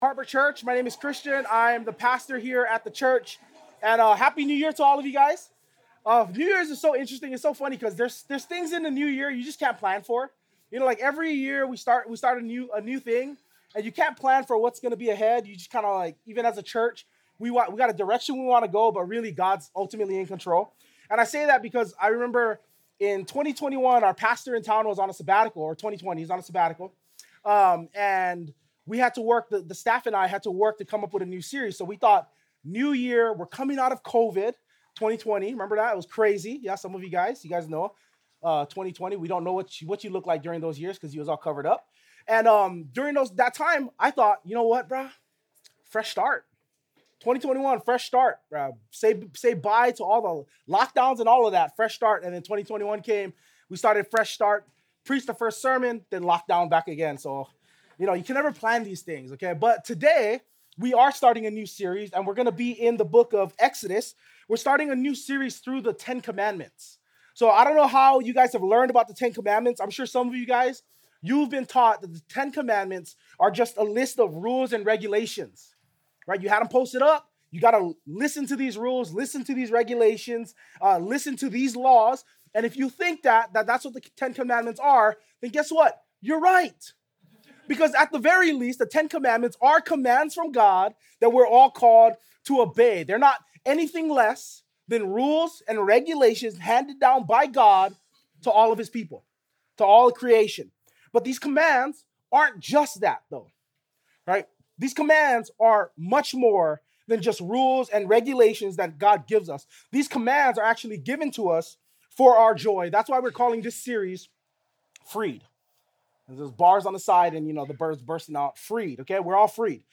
2025 Freed to Enjoy God Preacher